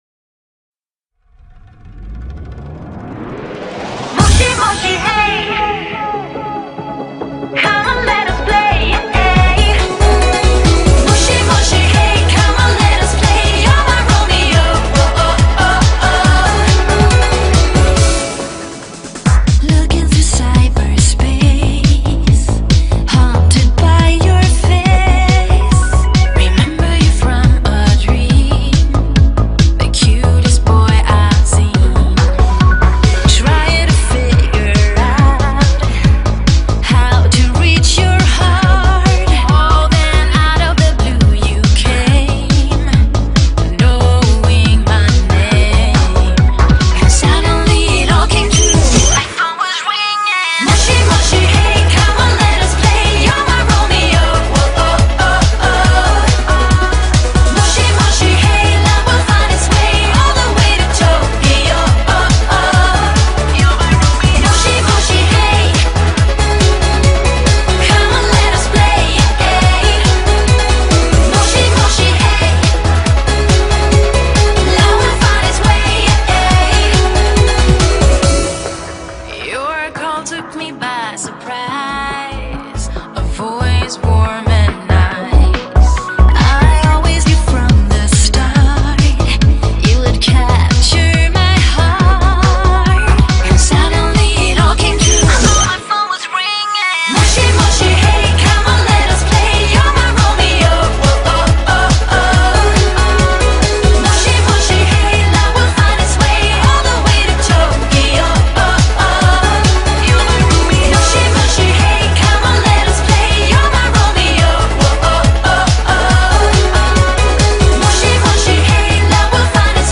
BPM70-139
Audio QualityCut From Video